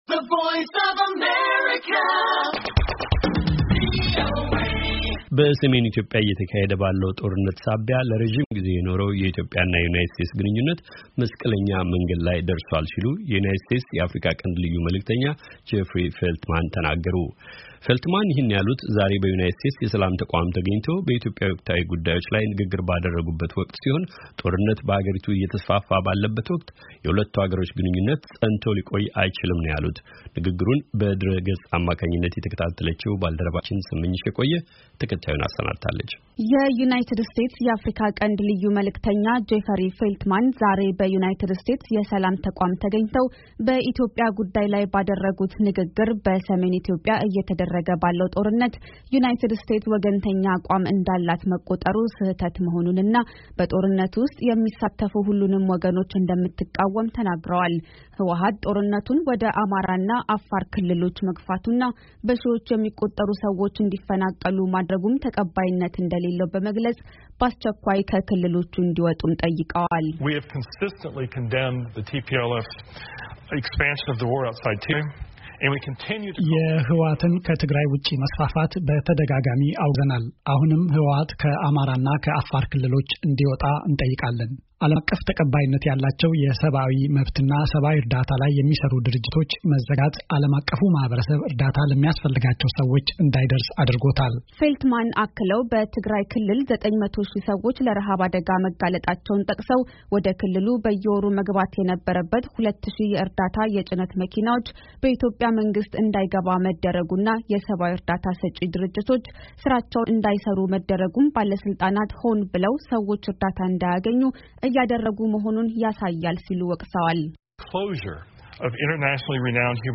በሰሜን ኢትዮጵያ እየተካሄደ ባለው ጦርነት ምክንያት ለረጅም ግዜ የኖረው የኢትዮጵያ እና የዩናይትድ ስቴትስ ግንኙነት መስቀለኛ መንገድ ላይ መድረሱን የዩናይትድ ስቴትስ የአፍሪካ ቀንድ ልዩ መልዕክተኛ ጄፈሪ ፌልትማን አስታውቀዋል። ፌልትማን ይህን ያሉት ዛሬ በዩናይትድ ስቴትስ የሰላም ተቋም ተገኝተው በኢትዮጵያ ወቅታዊ ጉዳይ ላይ ንግግር ባደረጉበት ወቅት ሲሆን ጦርነት በሀገሪቱ እየተስፋፋ ባለበት ወቅቱ የሁለቱ ሀገራት ግኙነት ፀንቶ ሊቆይ እንደማይችል ተናግረዋል።